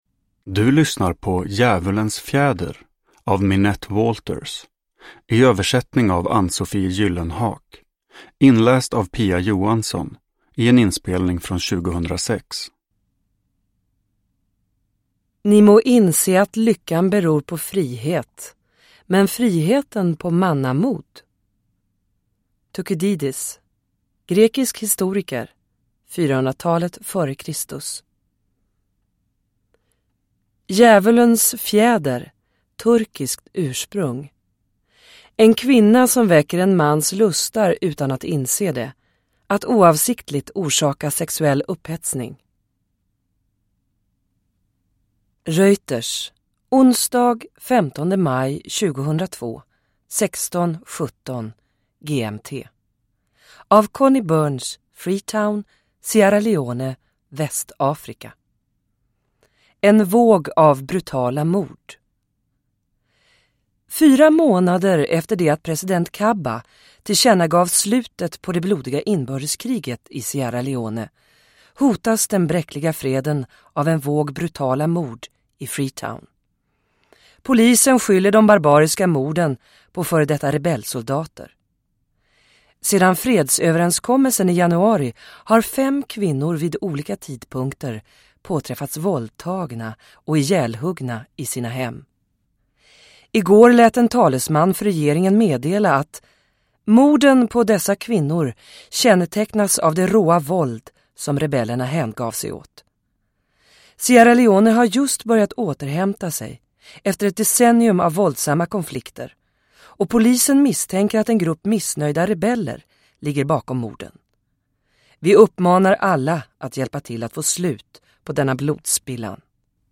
Djävulens fjäder – Ljudbok – Laddas ner